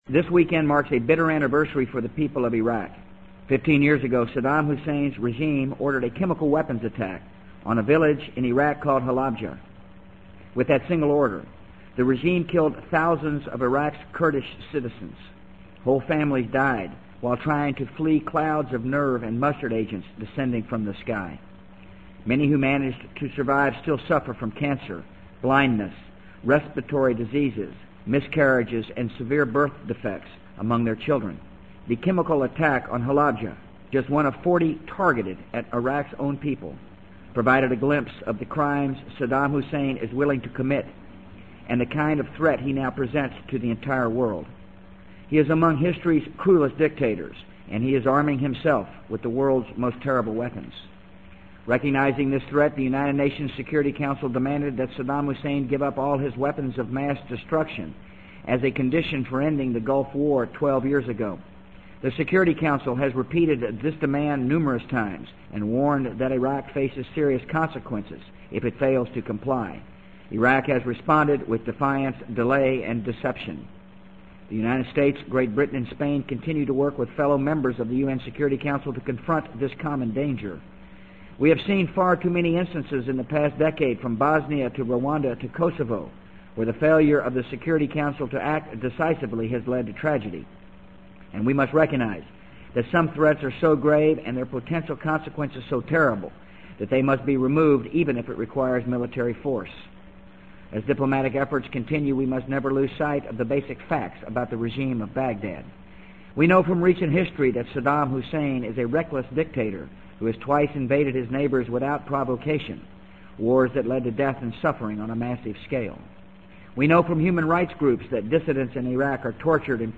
【美国总统George W. Bush电台演讲】2003-03-15 听力文件下载—在线英语听力室